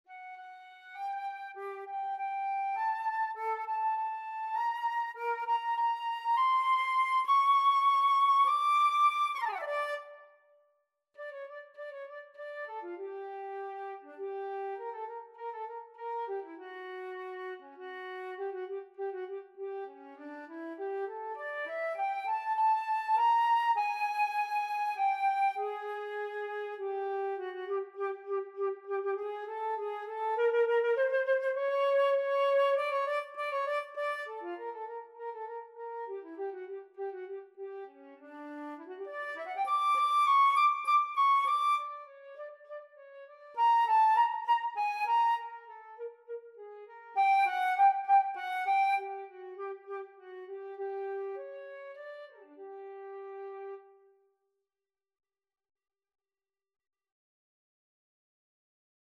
Nine-note scale b
flute-audio.mp3